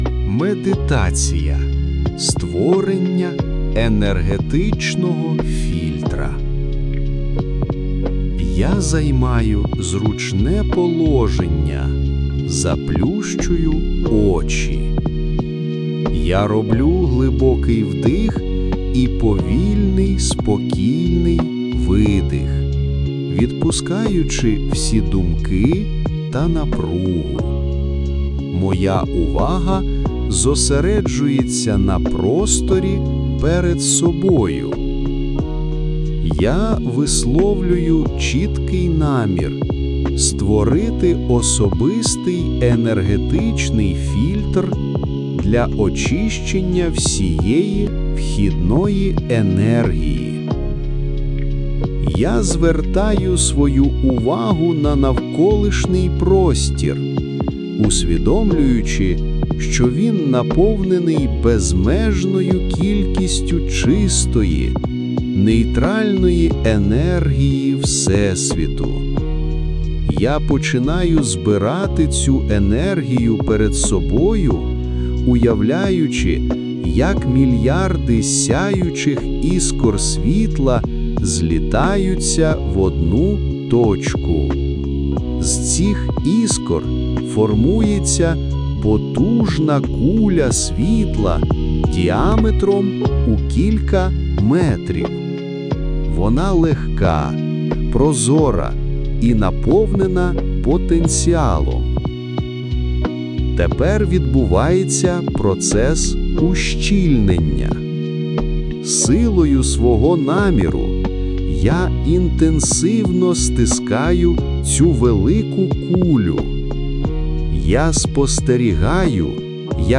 Медитація: Створення енергетичного фільтра
Весь матеріал медитації створено в Google AI Studio, включно із обладинкою.